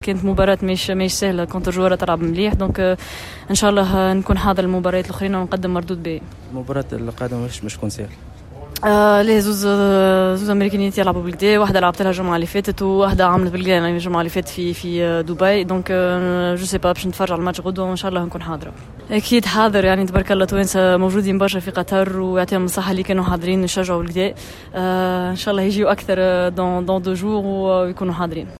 و هذا تصريح خاص بجوهرة اف ام